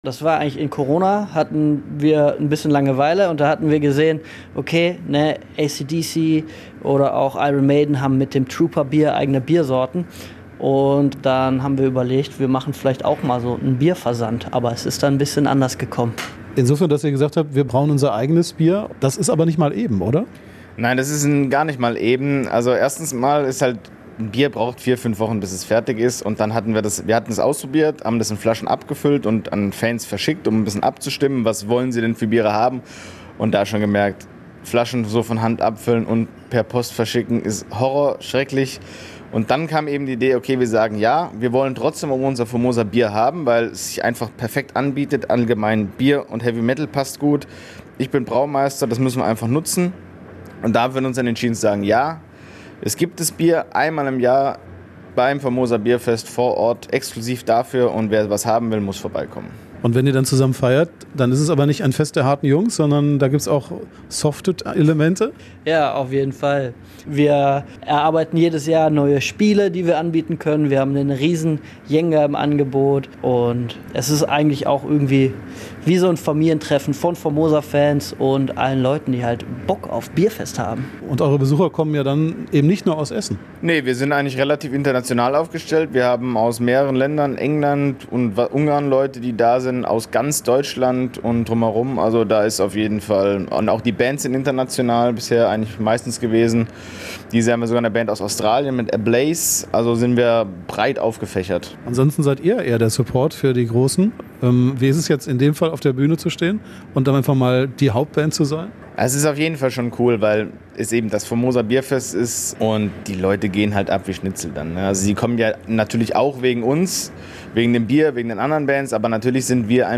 Zu Besuch im Probenraum von Formosa
Nebenan wird Bauschutt lautstark verarbeitet. Das ist auch durch das geschlossene Fenster deutlich zu hören.